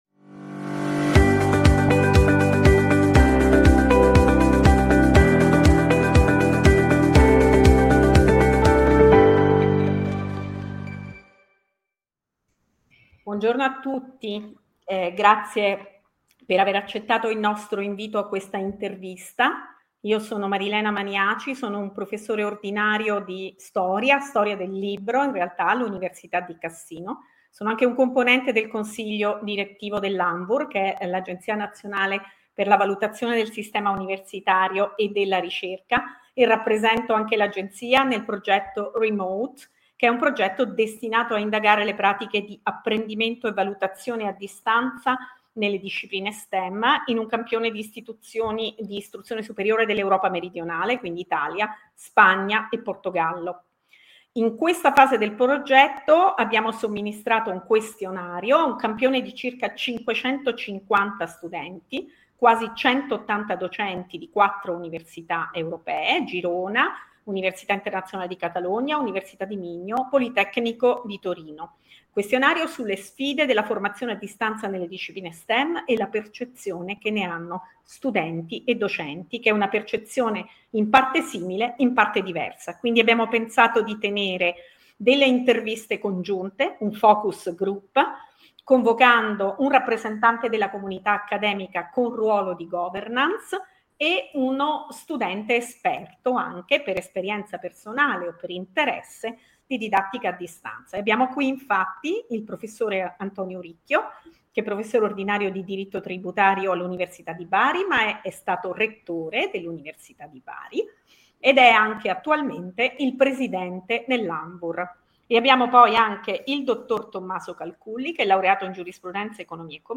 In-Depth Interviews